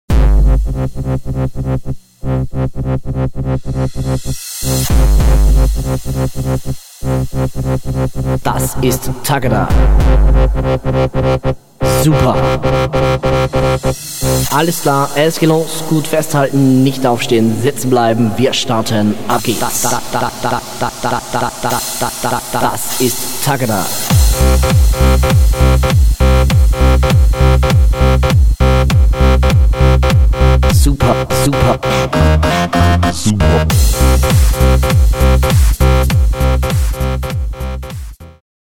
originalen Stimme (Vocals)